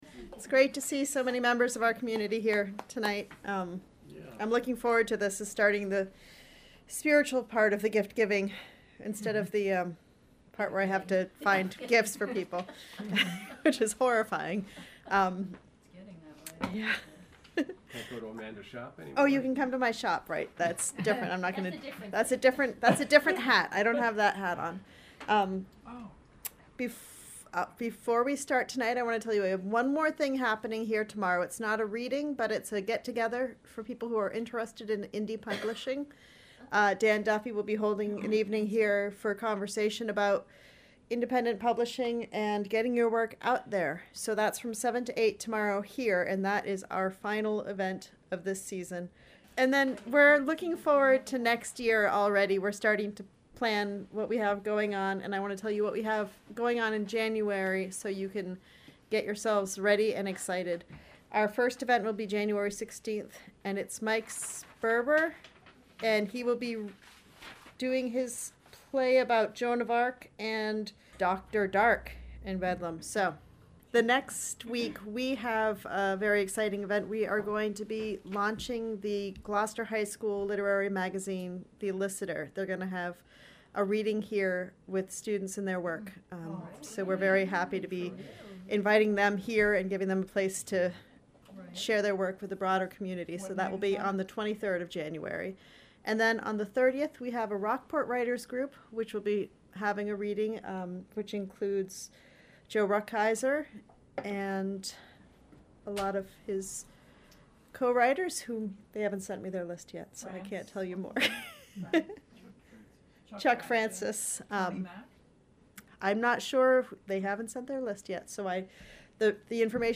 Community members share a poem they have been given as a gift, a poem given to them as a gift, or a poem dedicated to someone to share as we […]